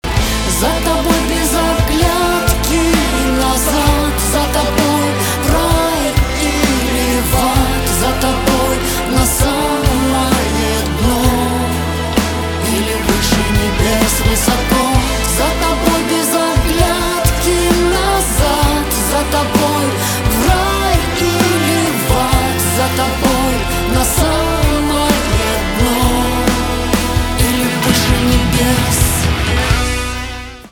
шансон
гитара , барабаны
чувственные , романтические